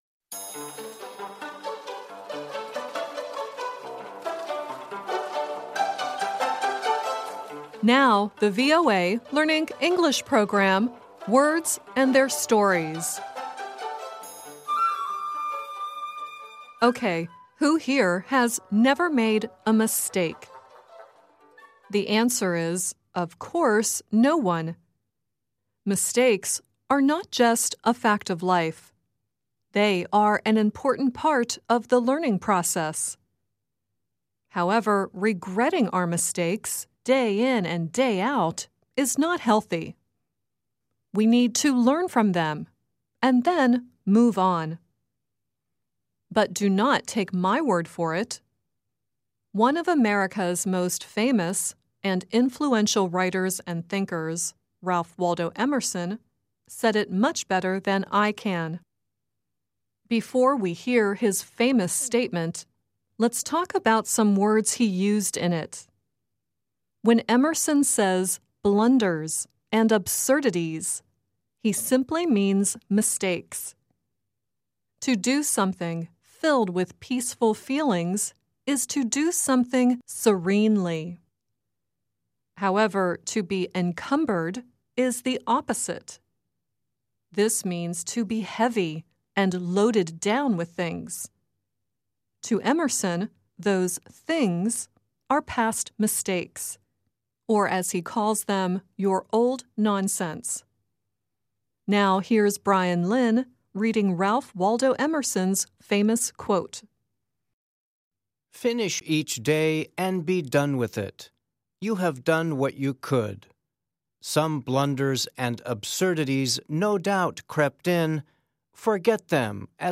The song earlier in the show is Buddy Holly singing "Early in the Morning." The song at the end is Boy George and Culture Club singing "Oil & Water."